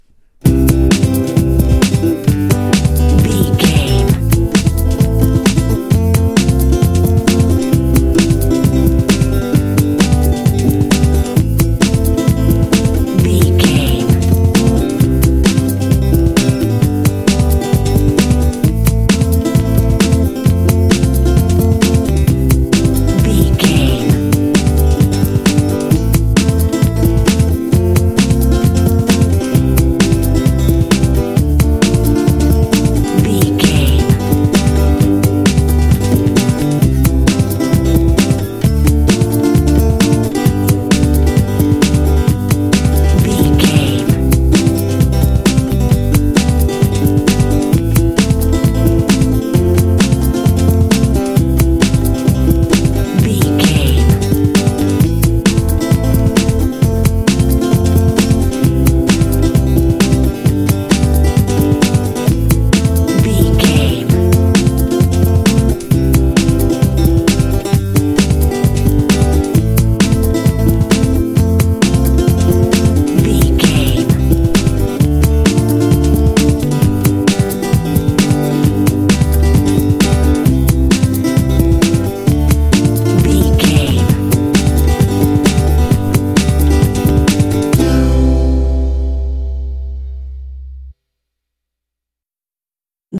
This fun bluegrass track is great for comedy games
Ionian/Major
happy
fun
drums
banjo
bass guitar
playful